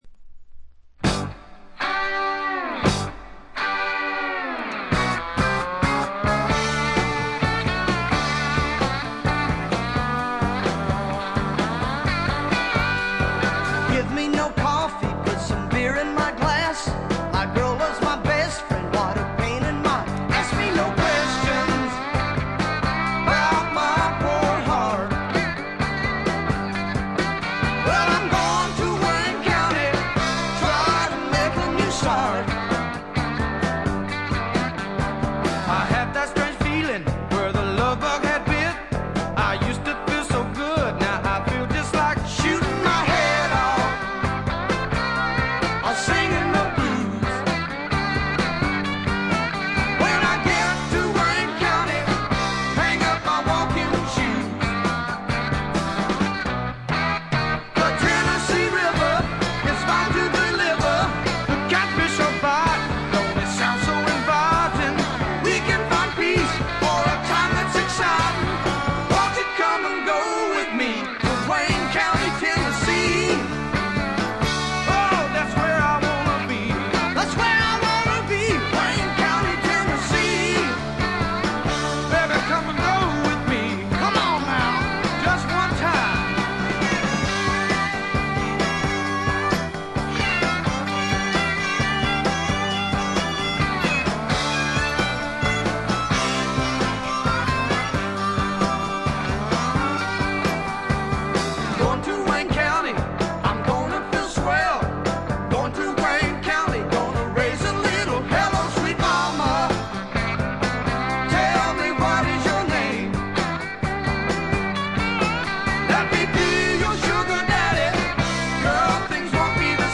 スワンプ・ロックとサザン・ロックの中間あたりの立ち位置ですかね。
試聴曲は現品からの取り込み音源です。
Guitar
Keyboards
Piano
Organ
Harmonica
Bass
Drums